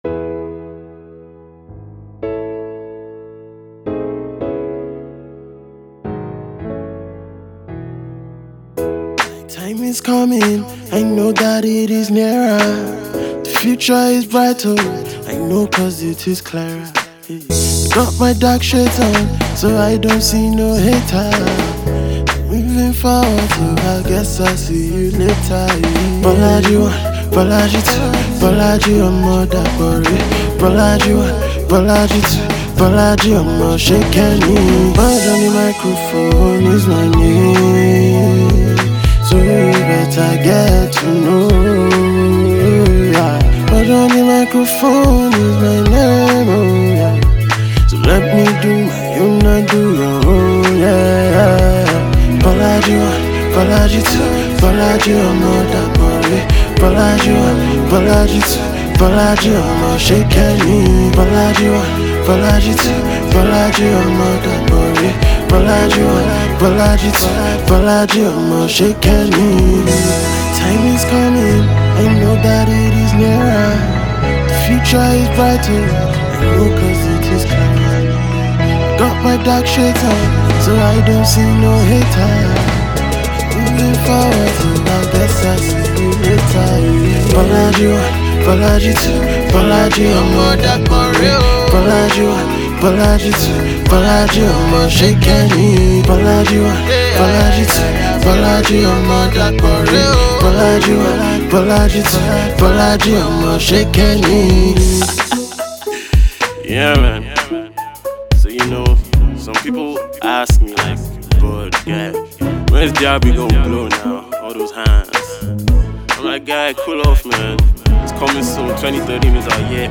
hit-making husky crooner of the Nigerian music industry